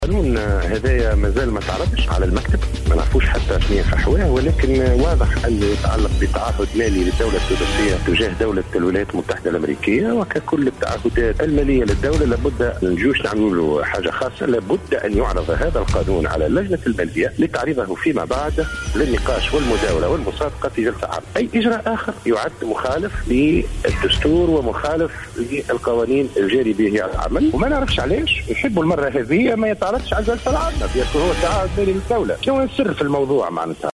قال النائب أحمد الصديق عن الجبهة الشعبية المعارضة اليوم الأربعاء إن هناك محاولات لعدم إحالة تسوية مالية مع السفارة الأميركية على جلسة عامة لمناقشتها.